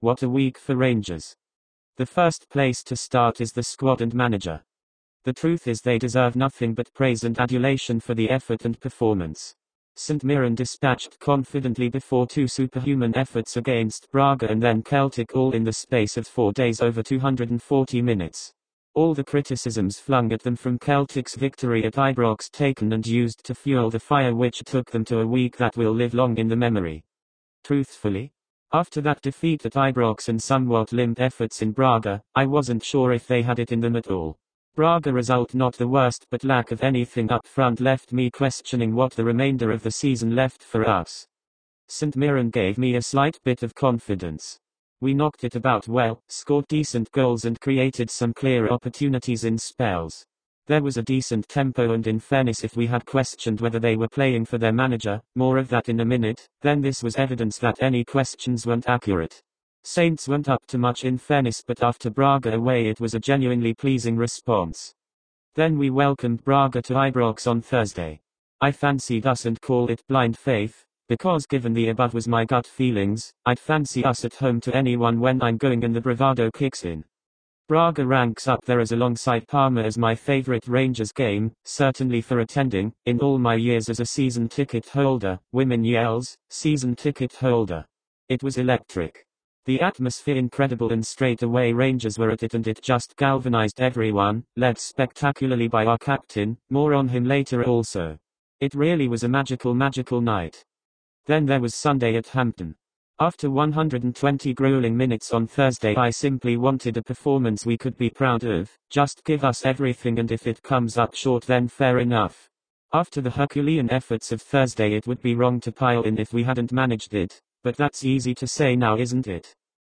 texttospeech.m4a